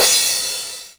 • Open Hi Hat Sample F# Key 24.wav
Royality free open hi hat one shot tuned to the F# note. Loudest frequency: 6078Hz
open-hi-hat-sample-f-sharp-key-24-504.wav